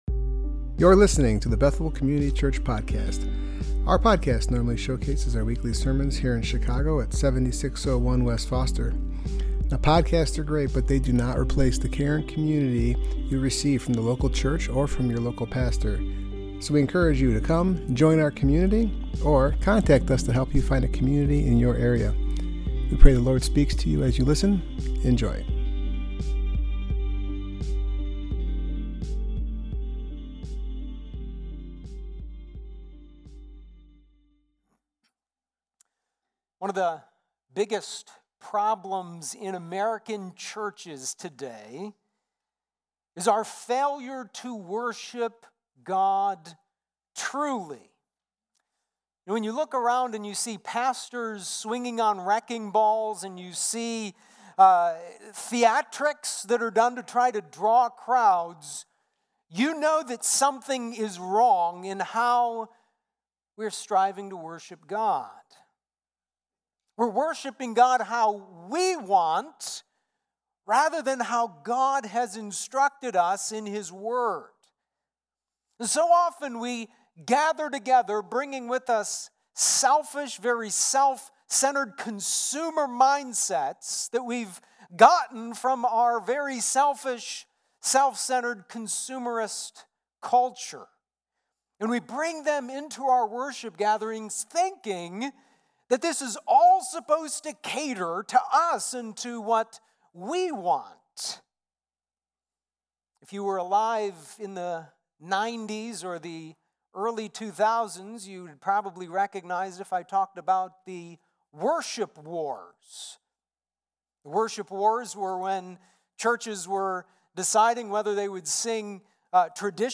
Passage: Ecclesiastes 5:1-7 Service Type: Worship Gathering